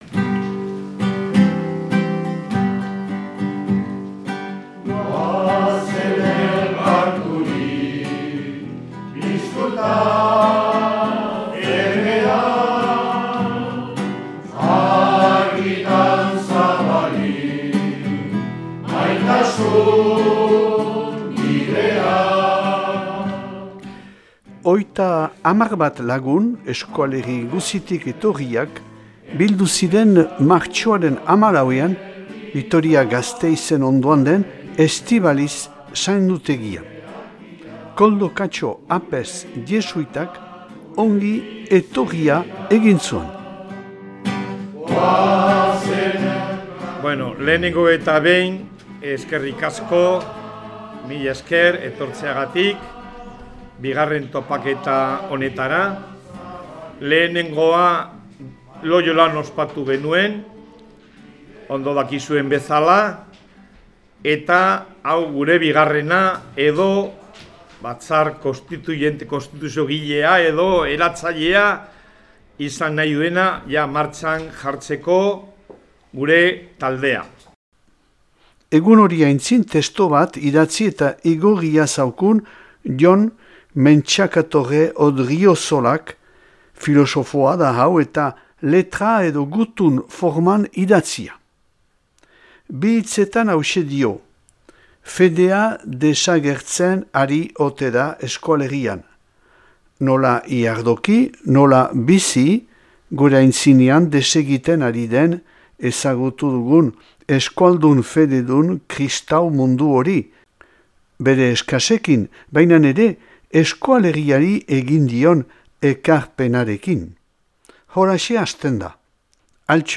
Martxoaren 14ean- Estibalitzeko monastegian.